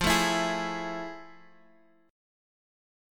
Fmaj7#9 chord